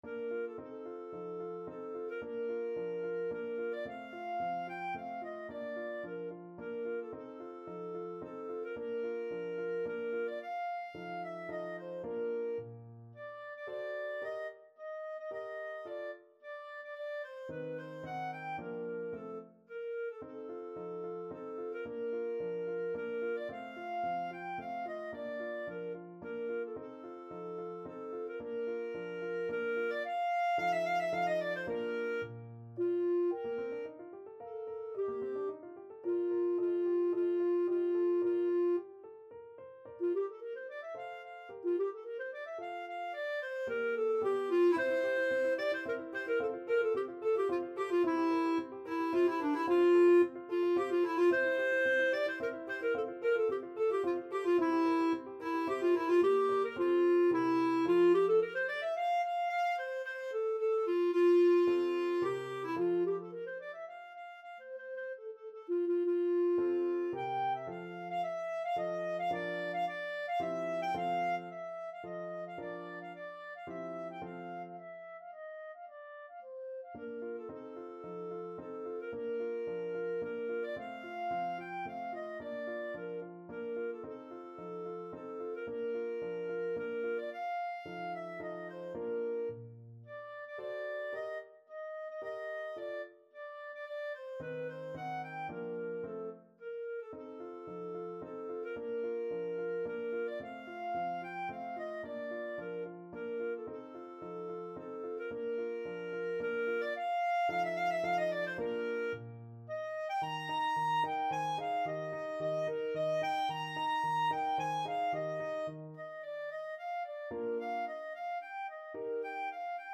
Clarinet version
3/4 (View more 3/4 Music)
Classical (View more Classical Clarinet Music)